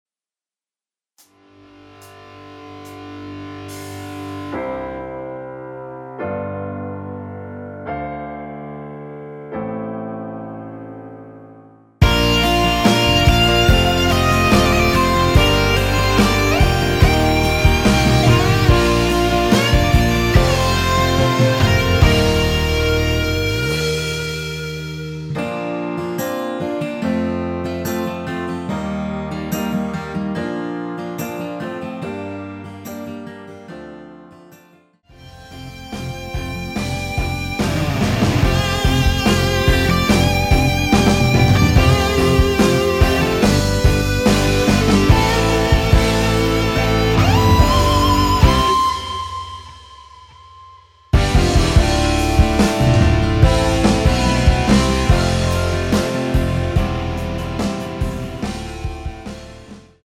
전주 없는 곡이라 전주1마디 만들어 놓았으며
피아노시작 되는 부분 부터 노래 들어가시면 되겠습니다.(미리듣기참조)
Bb
앞부분30초, 뒷부분30초씩 편집해서 올려 드리고 있습니다.
중간에 음이 끈어지고 다시 나오는 이유는